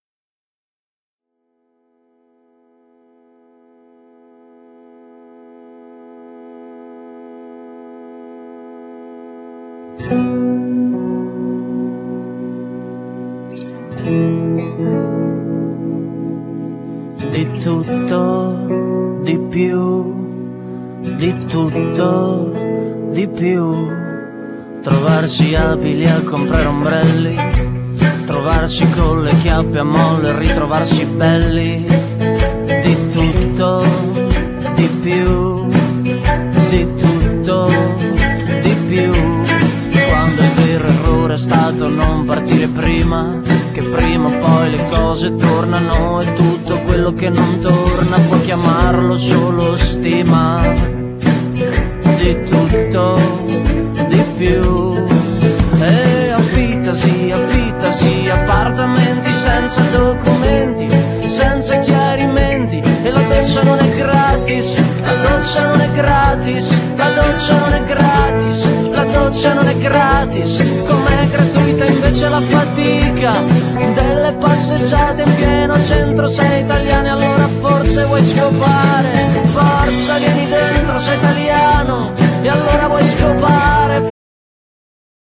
Genere : Pop elettrico